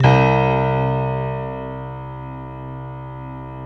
Index of /90_sSampleCDs/Optical Media International - Sonic Images Library/SI1_Six Pianos/SI1_Distantpiano